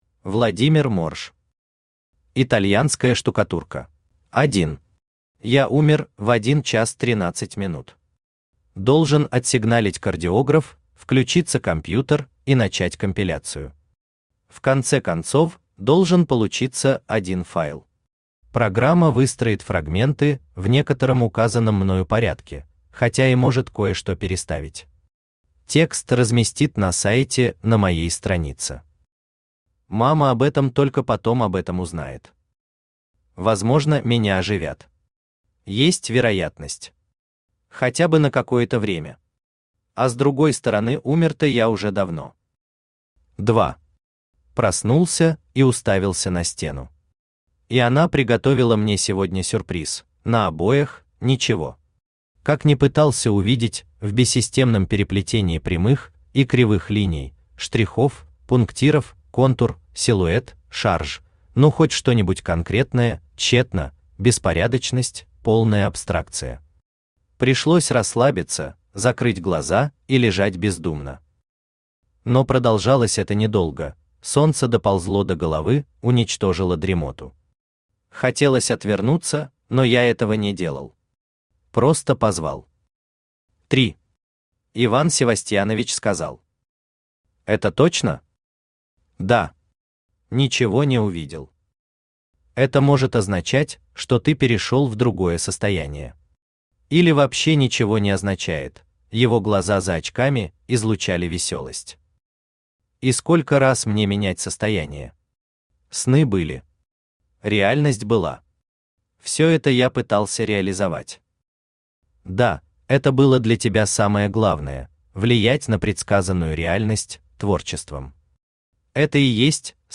Аудиокнига Итальянская штукатурка | Библиотека аудиокниг
Aудиокнига Итальянская штукатурка Автор Владимир Морж Читает аудиокнигу Авточтец ЛитРес.